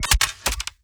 Added more sound effects.
GUNMech_Rocket Launcher Reload_11_SFRMS_SCIWPNS.wav